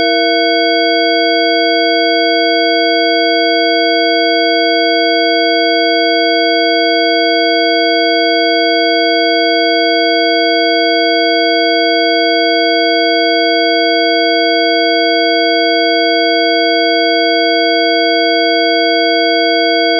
Sie sind 20 Sekunden lang, enthalten 6 bzw. 8 Kanäle mit 6 bzw. 8 verschiedenen Frequenzen von 330 Hz bis 2200 bzw. 4700 Hz mit 48 kHz Sample rate:
Sin8Ch48kHz_E6.WAV